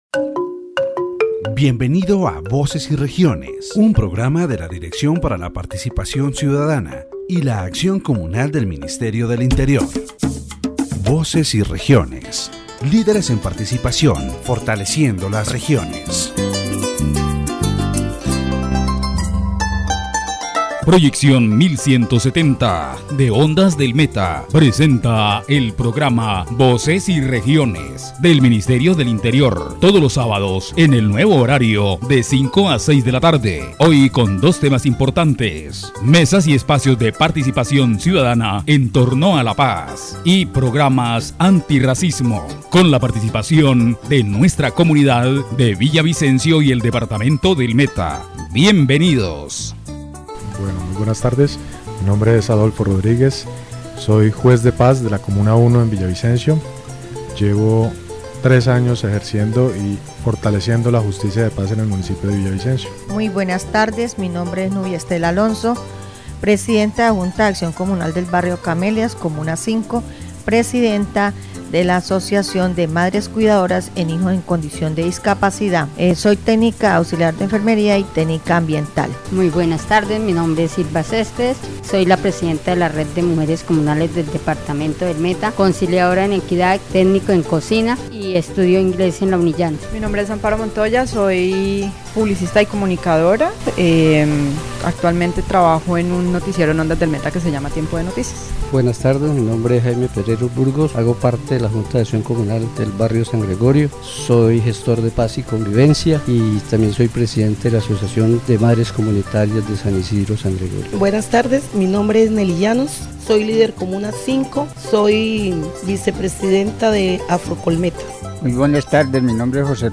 The radio program "Voces y Regiones" delves into the role of community justice, with special emphasis on the work of justices of the peace in Colombia. The debate highlights the challenges and opportunities of this form of justice, particularly in the context of the ongoing peace process in the country. Key topics discussed are: community justice, challenges facing justices of the peace, the role of justices of the peace in peacebuilding and the need for government support.